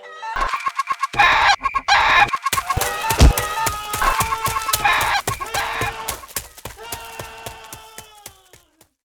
squeak1.wav